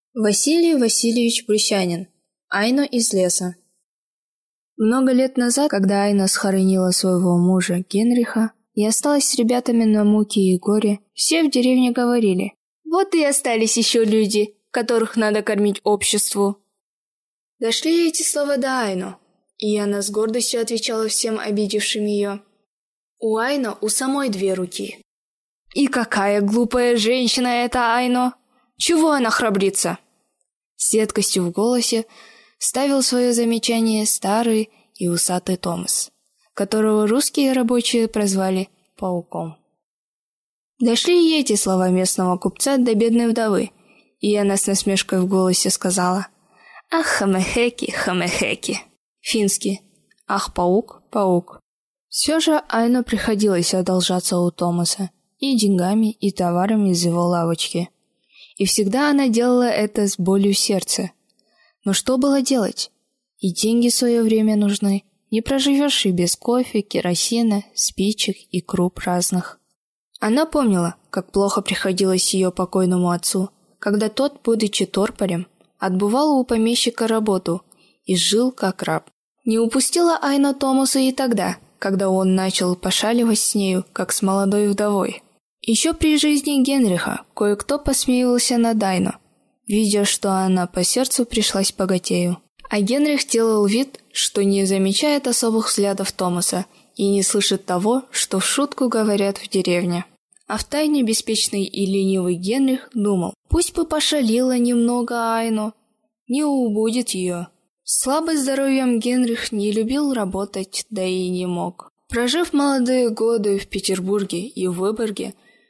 Аудиокнига Айно из леса | Библиотека аудиокниг